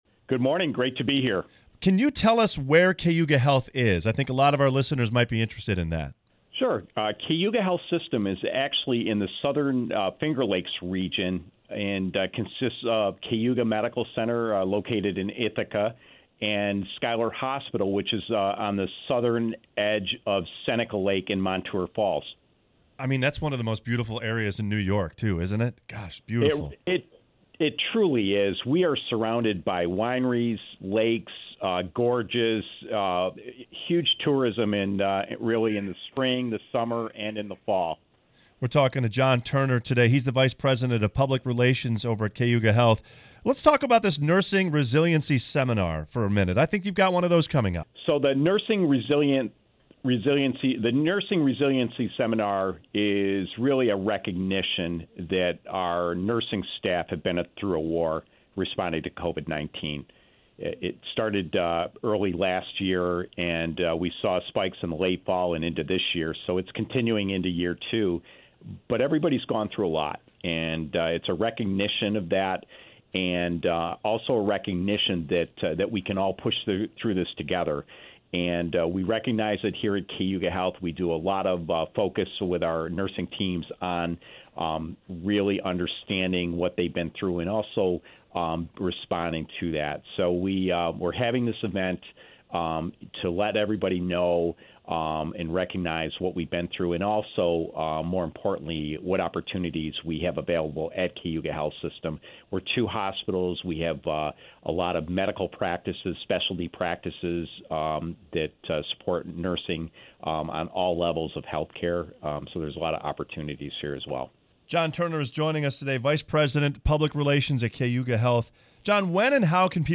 WYRK Radio Interview: Nursing Resiliency Seminar | Cayuga Health